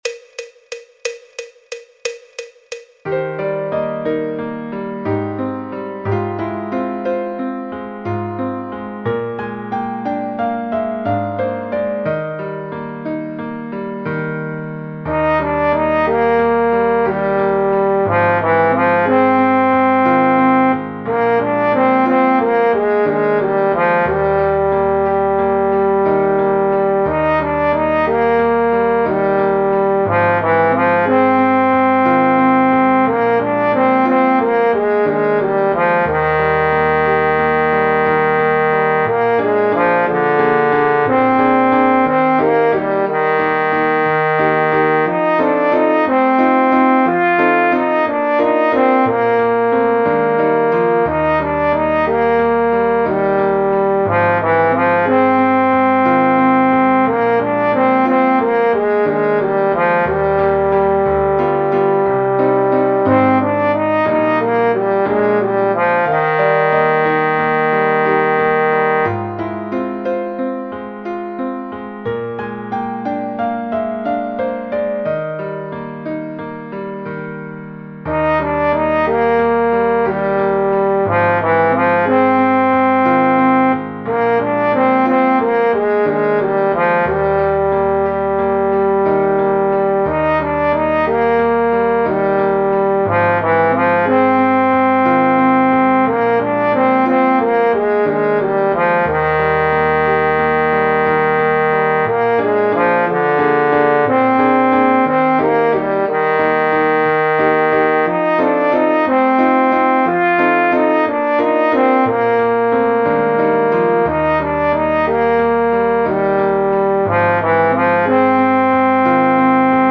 El MIDI tiene la base instrumental de acompañamiento.
Música clásica
Trombón / Bombardino